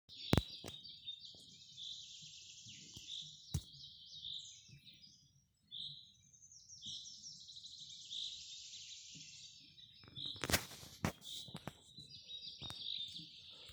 Birds -> Finches ->
Chaffinch, Fringilla coelebs
StatusVoice, calls heard